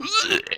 sounds_vomit_04.ogg